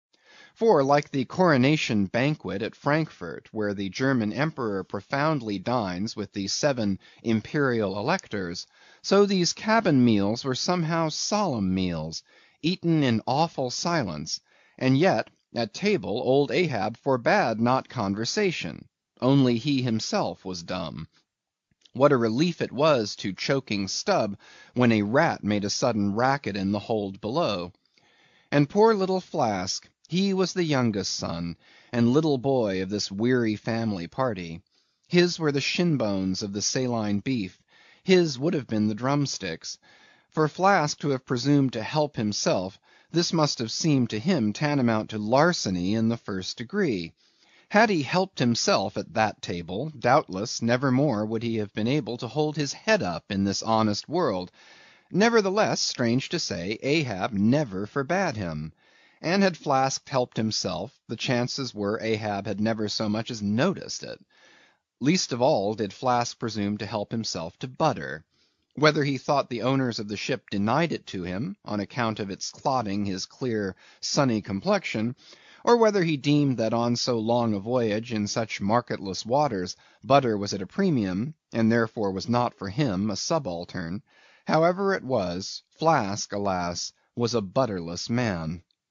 英语听书《白鲸记》第395期 听力文件下载—在线英语听力室